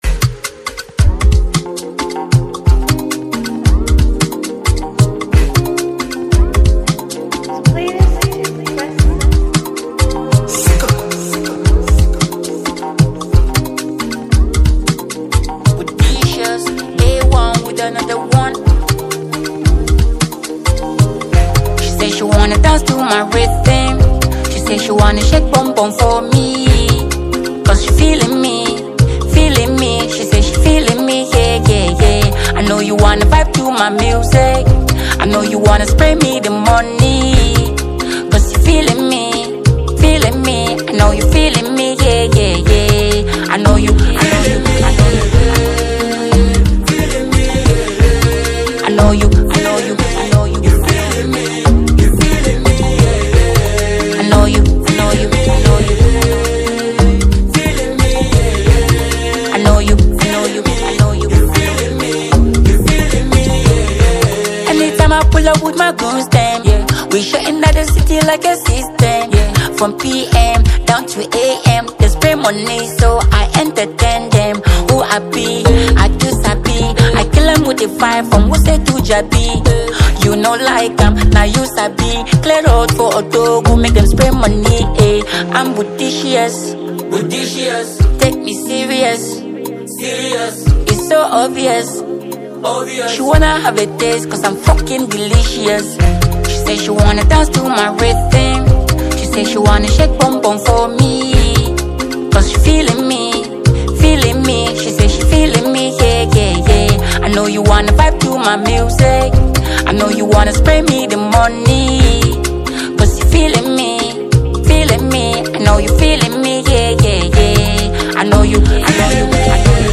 emphatically fuses hip-hop with Local women choir